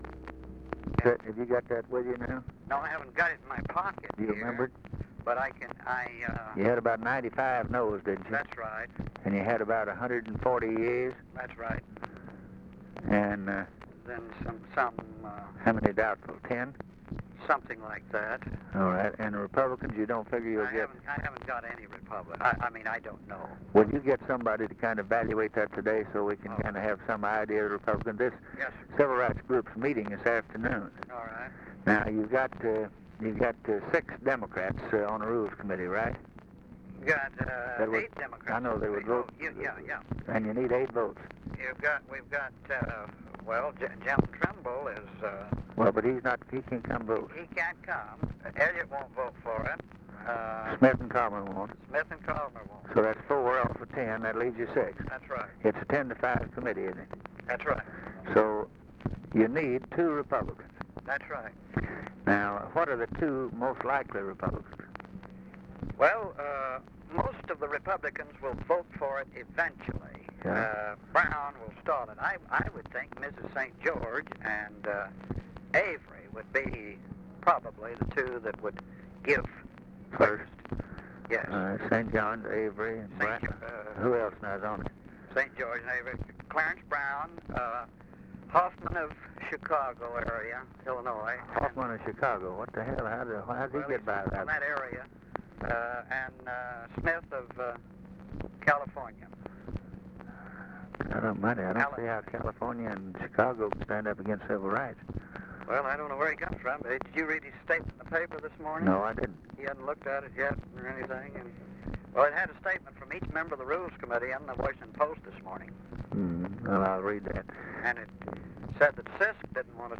Conversation with CARL ALBERT, December 4, 1963
Secret White House Tapes